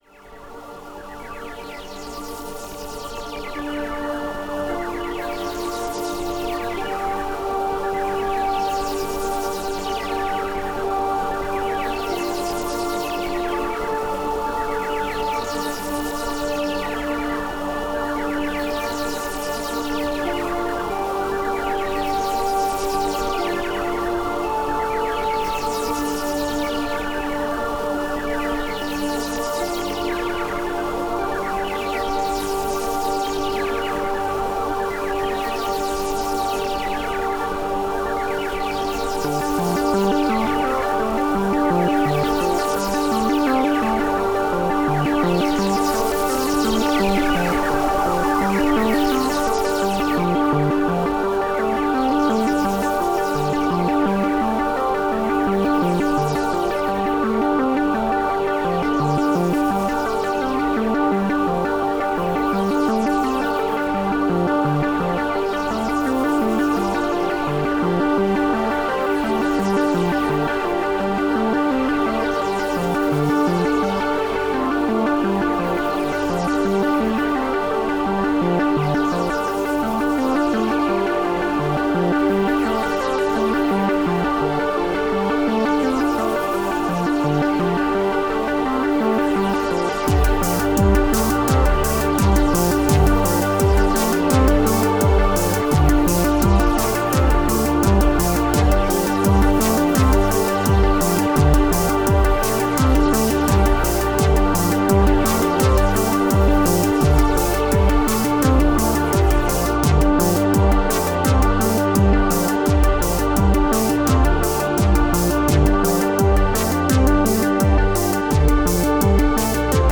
-Electro Industrial Disco- Gemafreie Musik
Tempo: 75 bpm / Datum: 01.02.2022